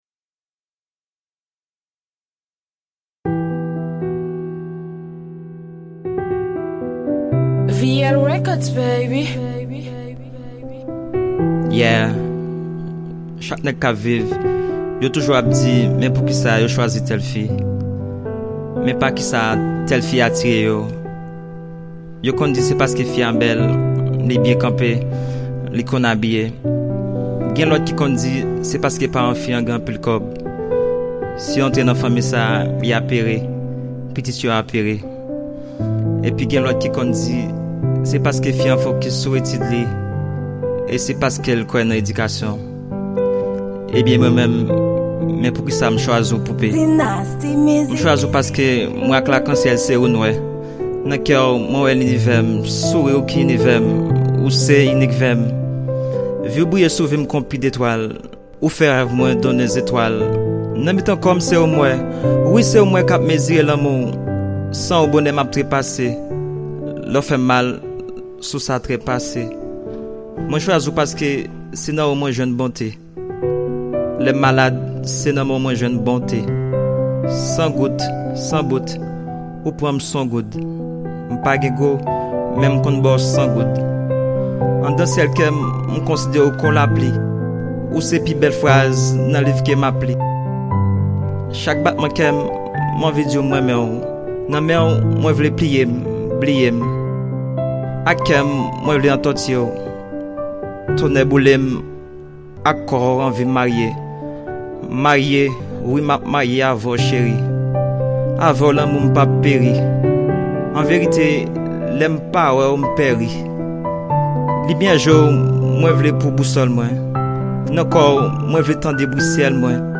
Genre: Slam